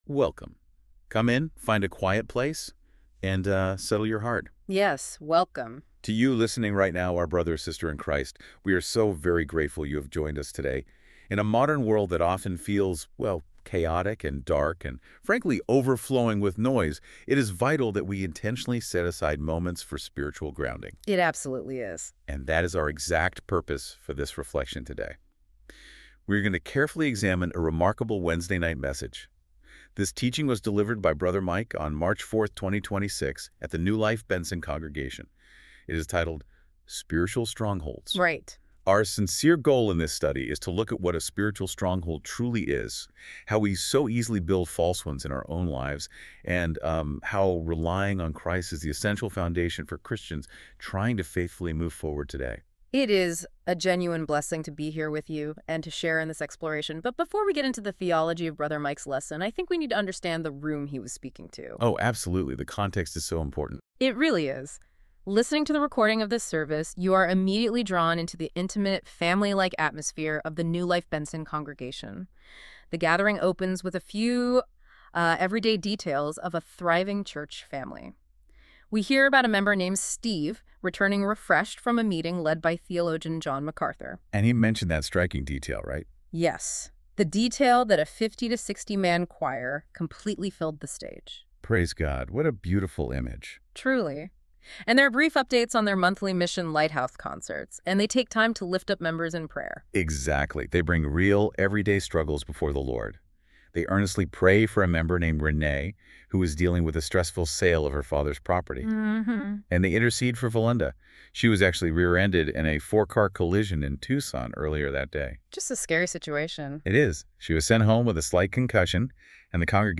Sermons | NEW LIFE FAMILY WORSHIP CENTER